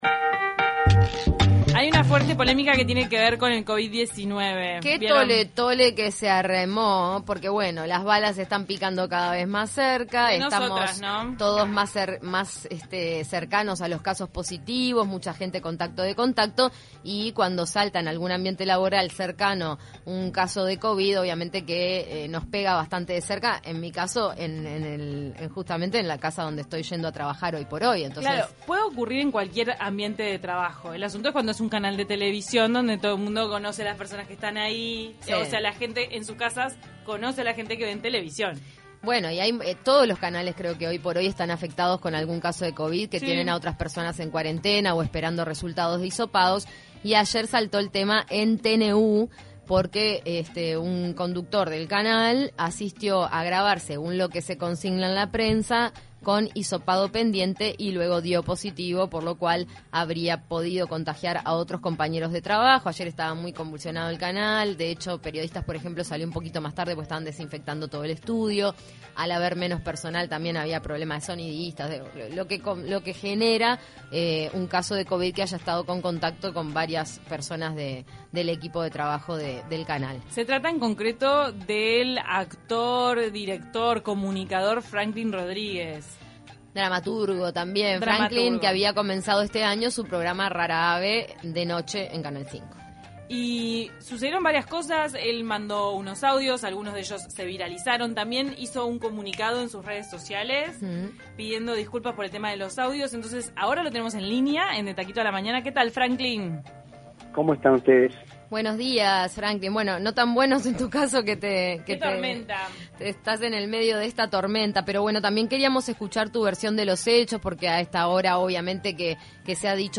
Escuche la entrevista de De Taquito a la mañana